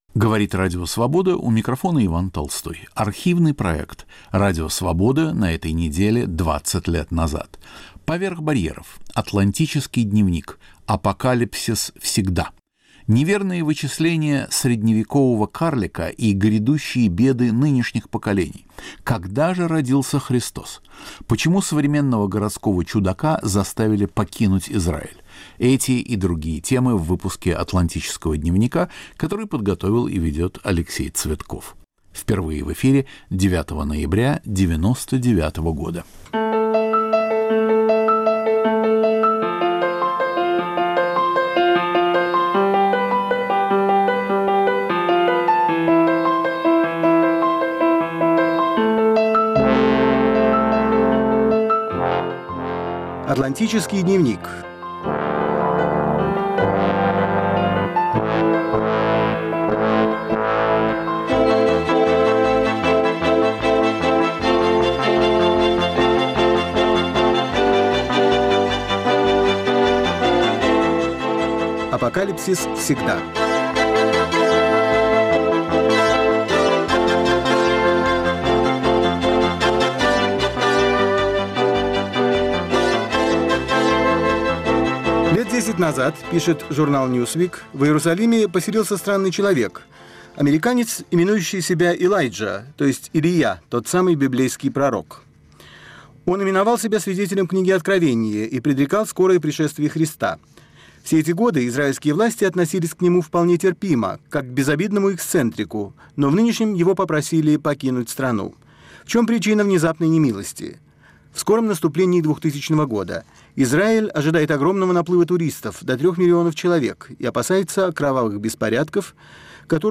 Архивный проект.